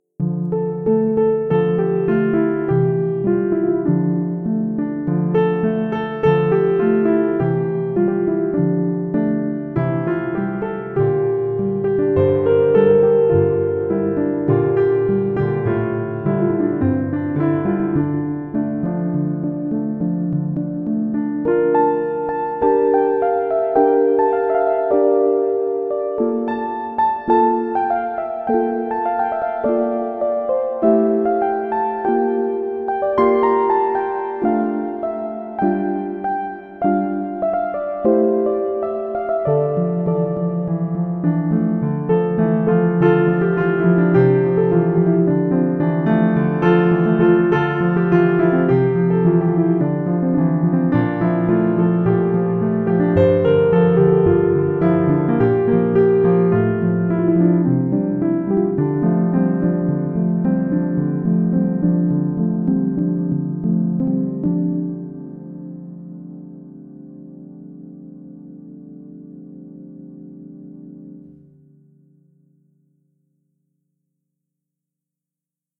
Αρχιμηνιά σε λίγο πειραγμένη εναρμόνιση. Γραμμένο με qtractor, pianoteq και OB-Xd.